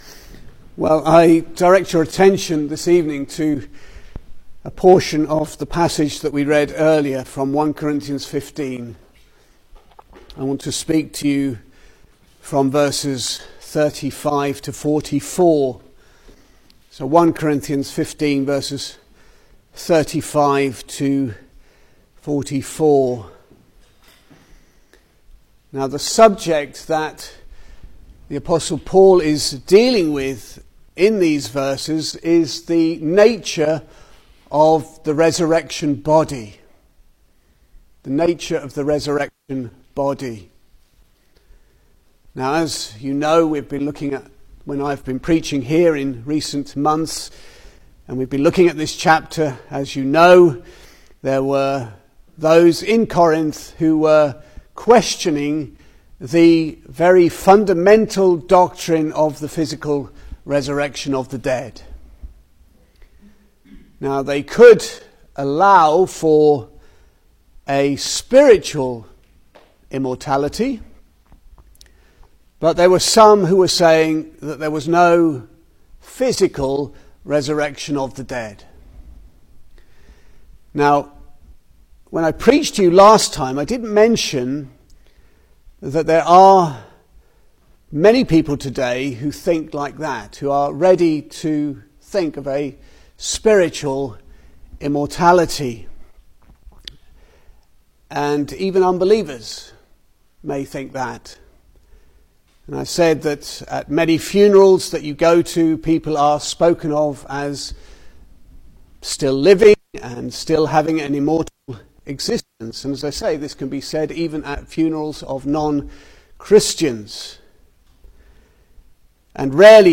Single Sermons